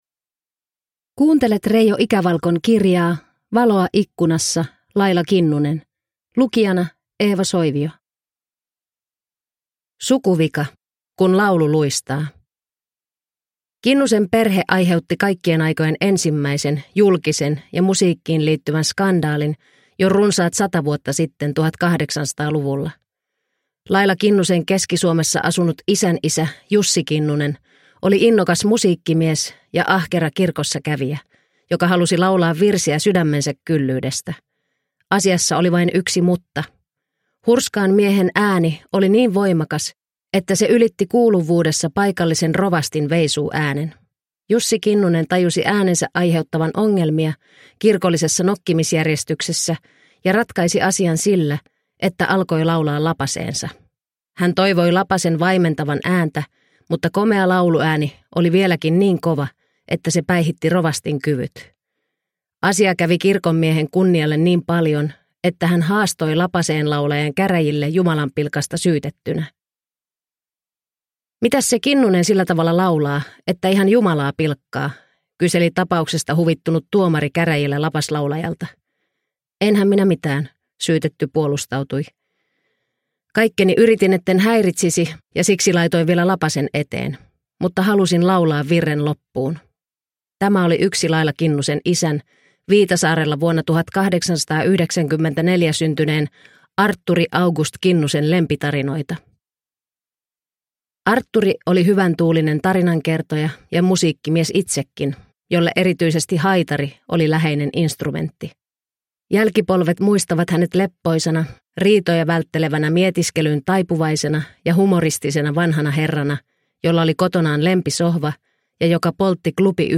Valoa ikkunassa - Laila Kinnunen – Ljudbok – Laddas ner